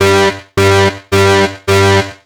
Hacked.wav